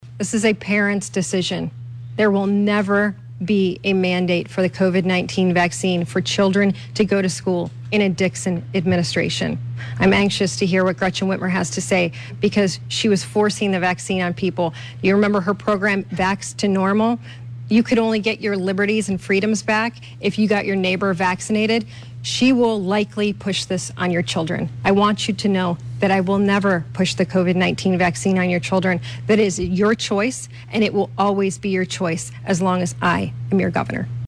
The debate brought the two contenders together for hour-long event at Oakland University.